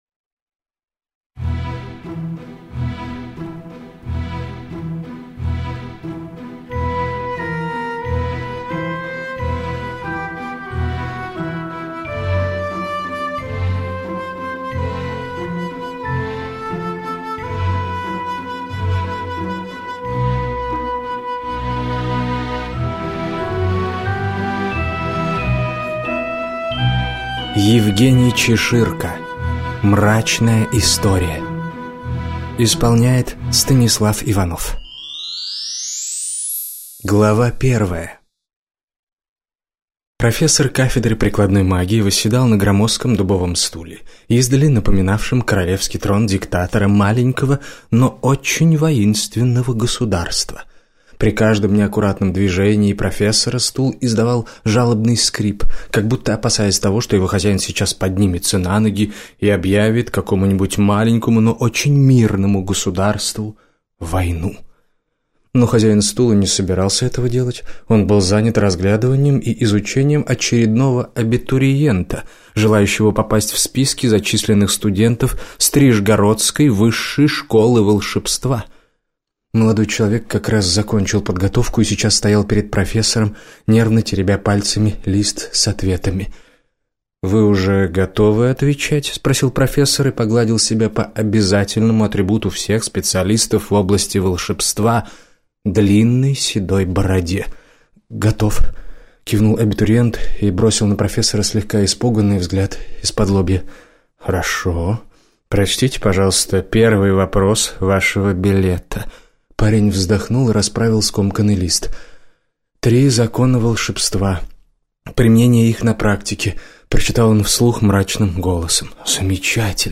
Аудиокнига Мрачная история - купить, скачать и слушать онлайн | КнигоПоиск